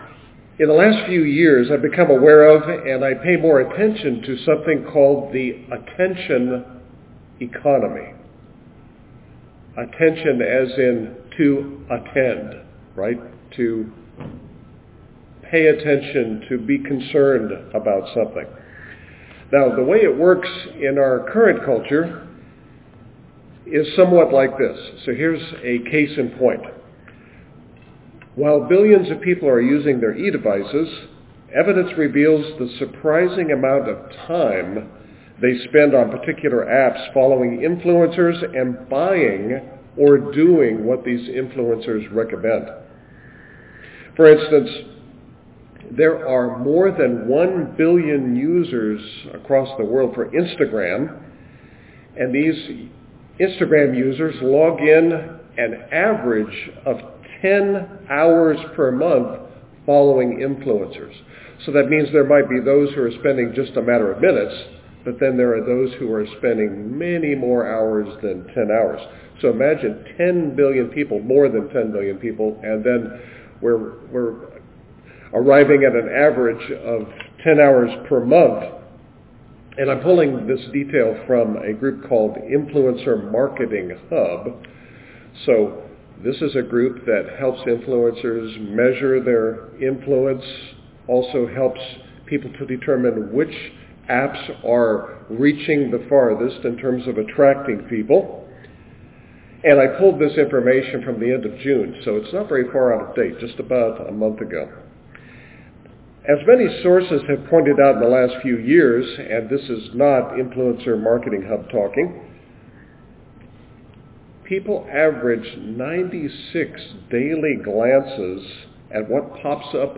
Sermons
Given in Cincinnati North, OH